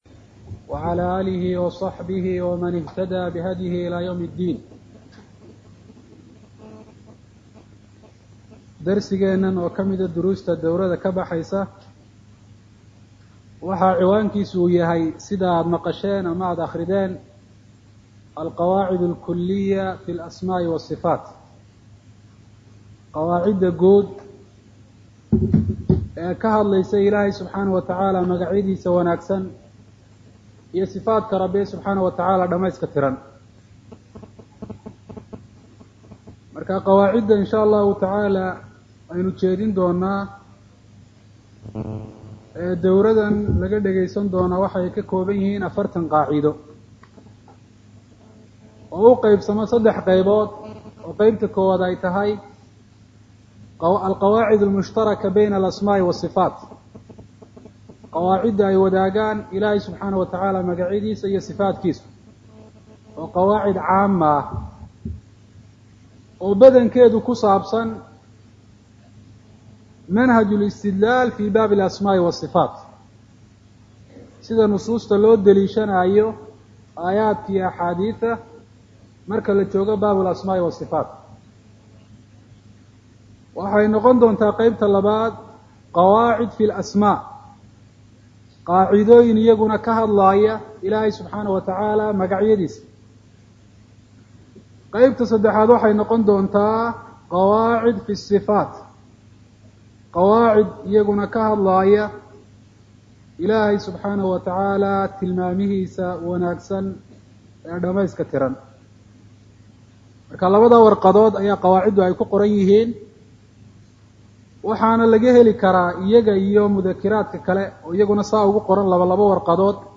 Sharaxa Al-Qawaacidul Kuliyyah Fil Asmaa Wal-Siffaat – Darsiga 1aad